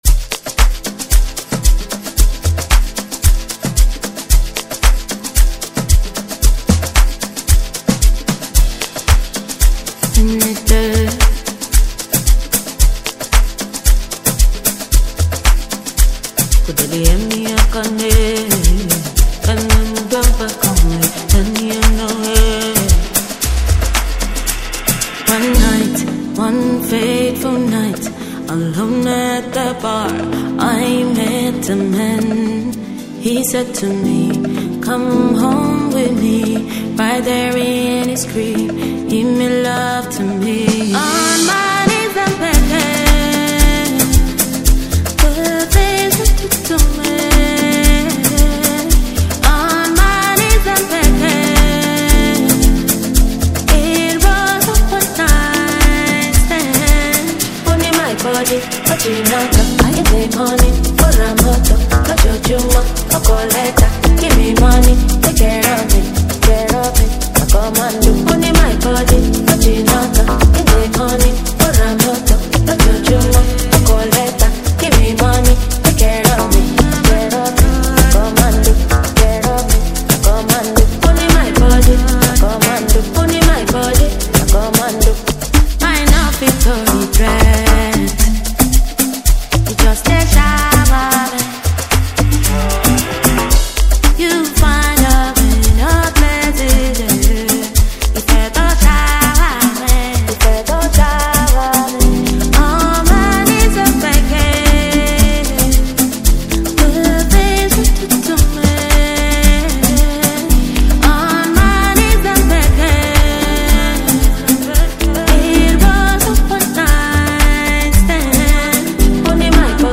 Nigeria Talented Afro House Queen